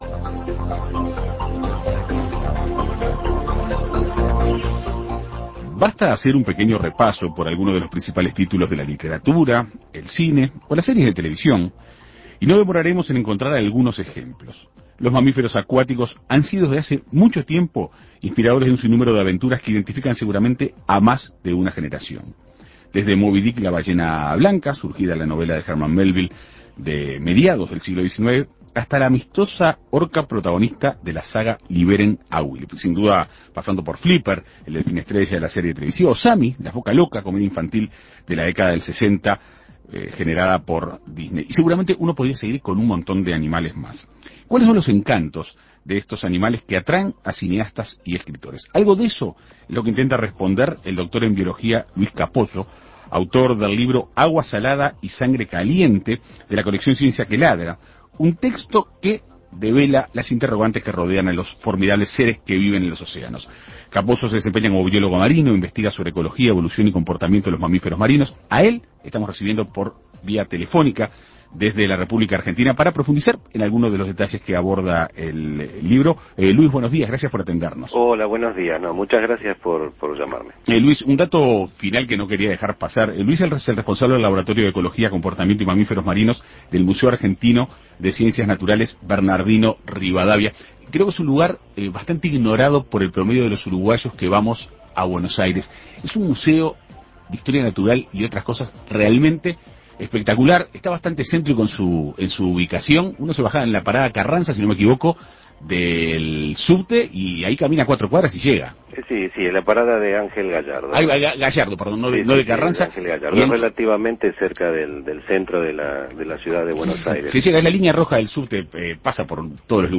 En Perspectiva Segunda Mañana dialogó con el investigador.